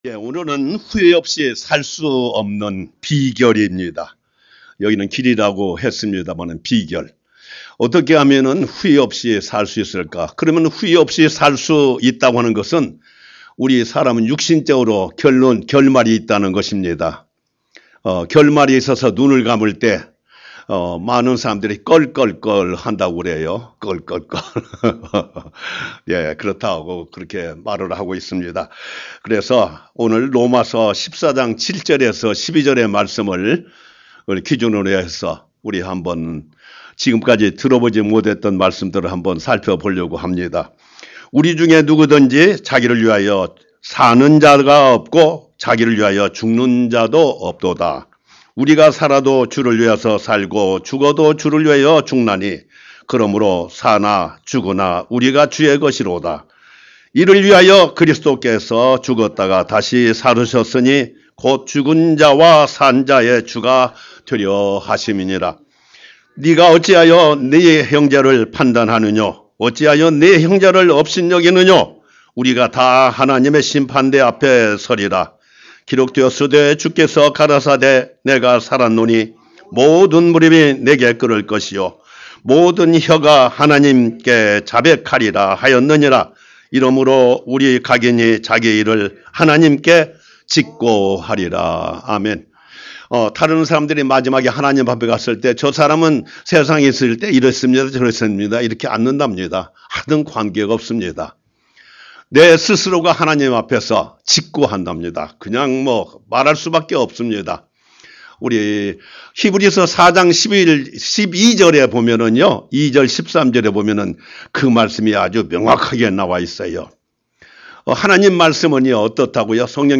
Sermon - 후회없이 살수 있는 비결 The secret to living a life without regrets.